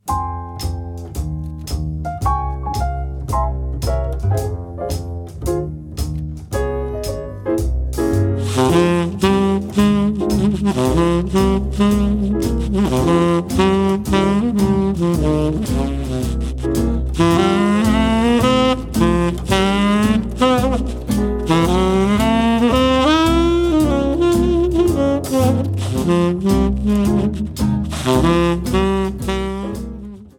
trumpet
trombone
alto sax, baritone sax, clarinet, vocals
piano
guitar
bass
drums